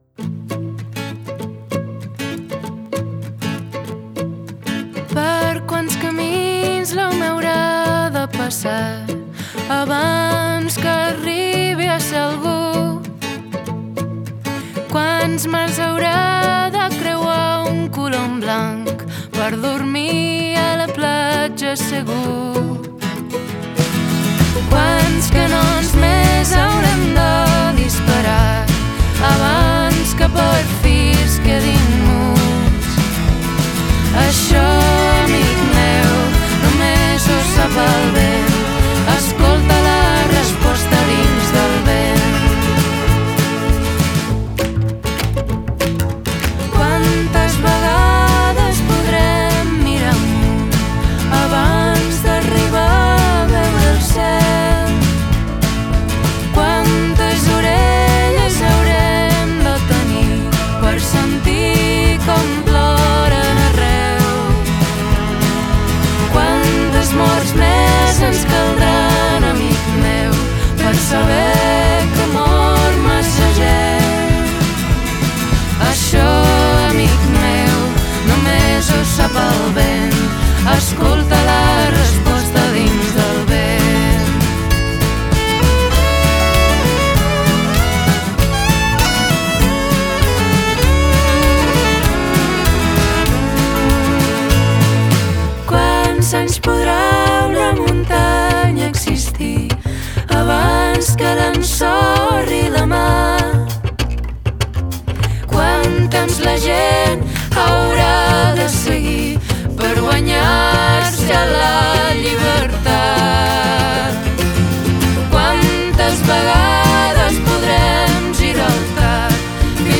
folk progressiu